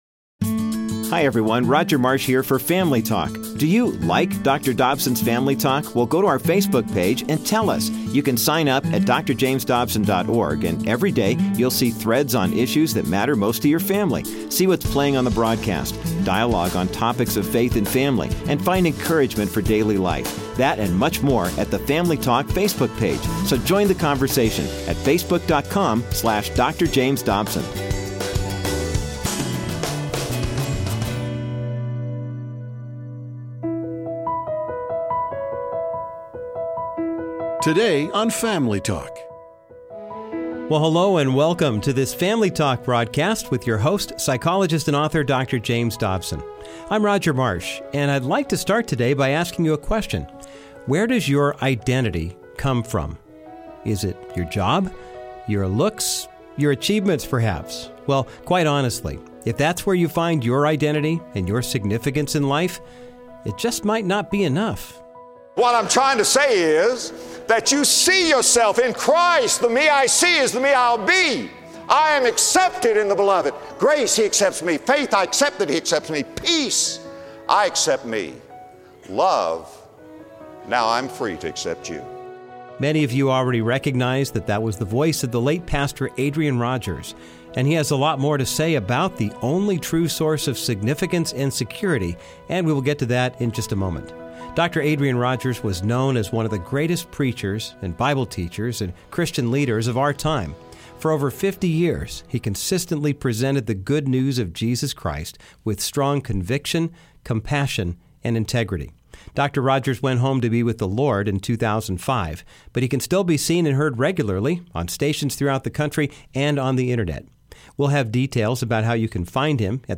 All of us long for significance, sufficiency, and security, and we often look to our jobs, our relationships, or our accomplishments to give us these things. But on todays classic broadcast